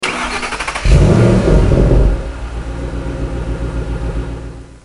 Index of /server/sound/vehicles/tdmcars/hsvgts
enginestart.mp3